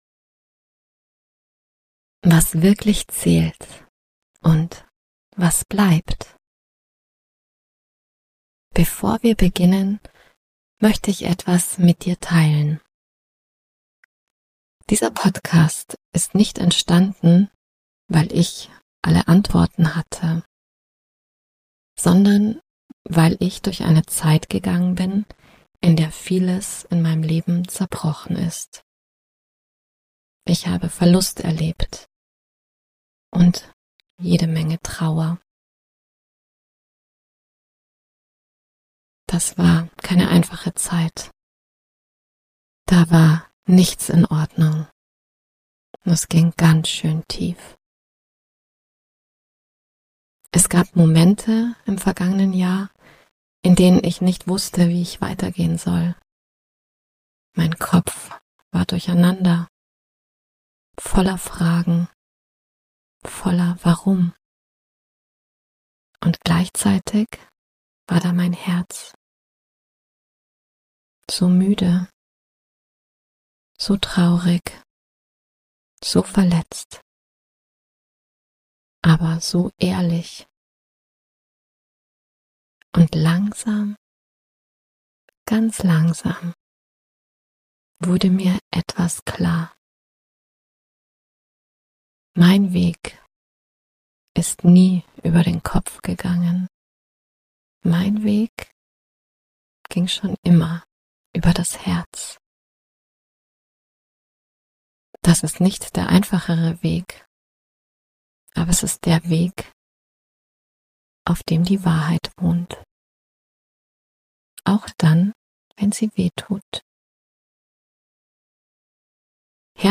Mit einer sanften Meditation, einem Segenswort und dem leisen Gedanken: Es könnte was werden.